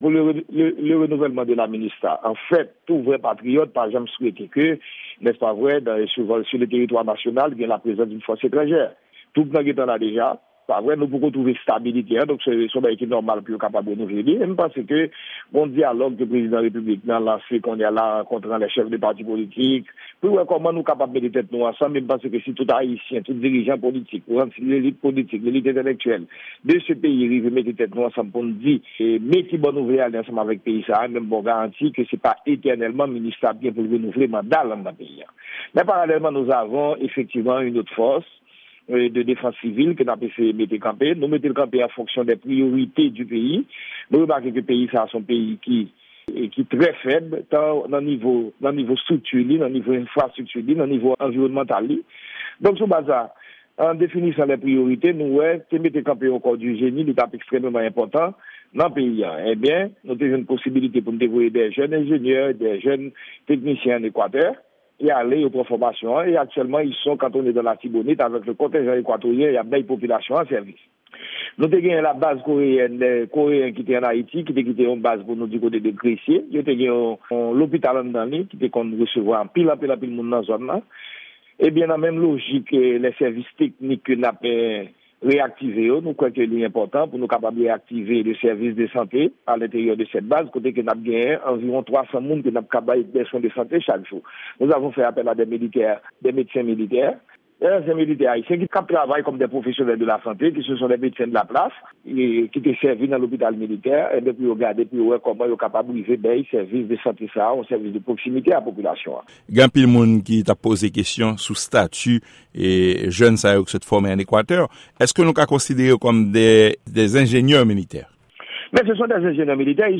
Deklarasyon minis defans ayisyen an, Rudolphe Joasile